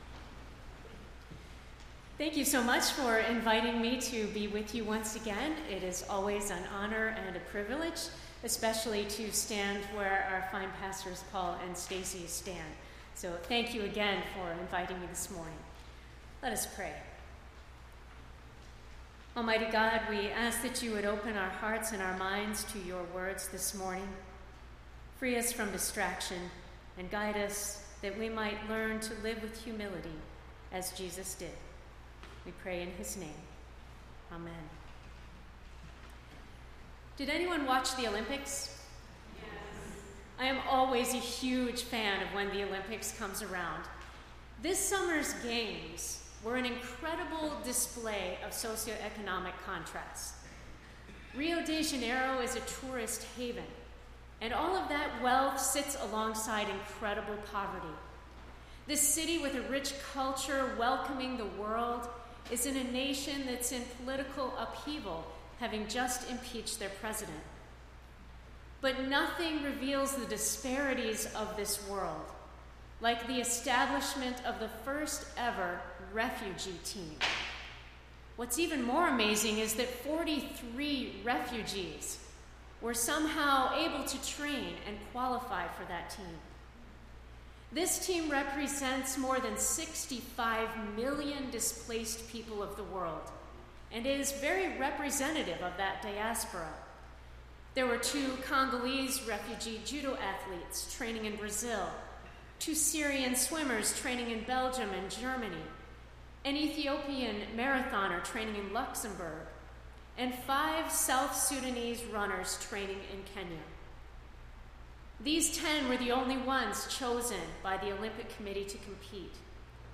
8-28-16-sermon.mp3